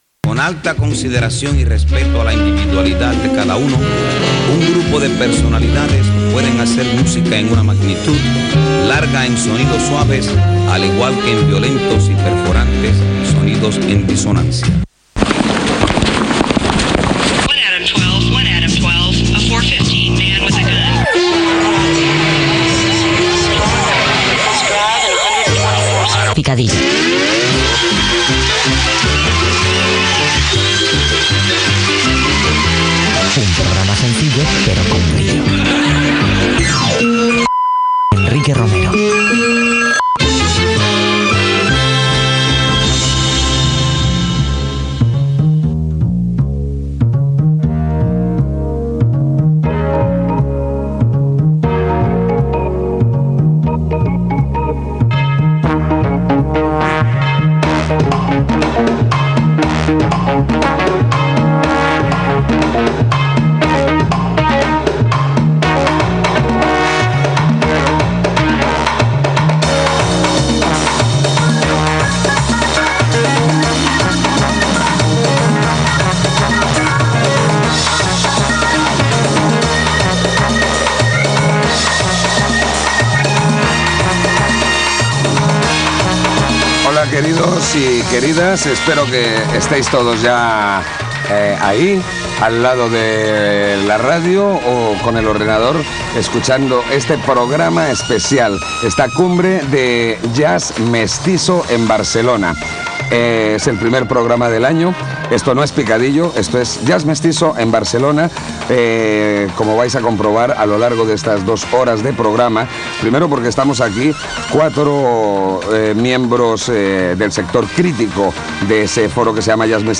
Careta del programa, presentació, espai dedicat al jazz mestís a Barcelona
Gènere radiofònic Musical